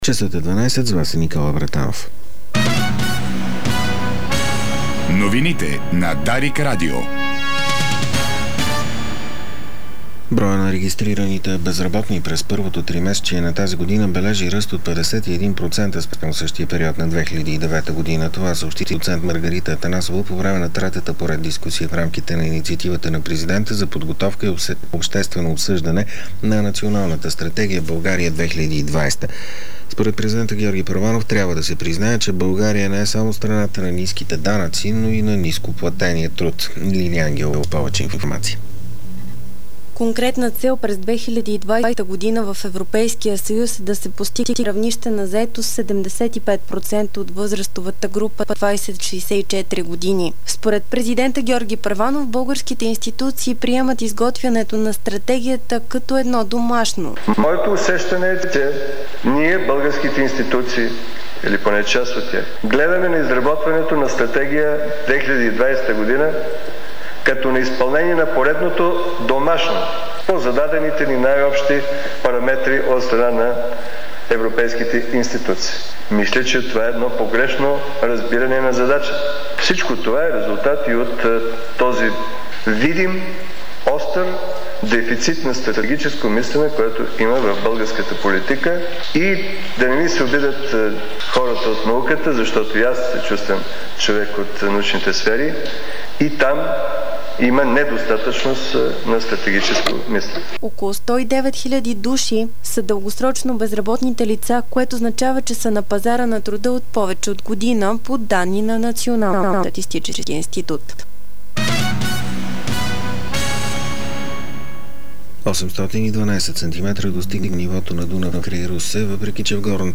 Обедна информационна емисия - 03.07.2010